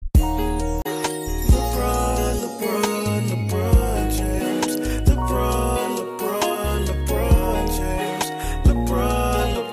lebron rnb sound effects
lebron-rnb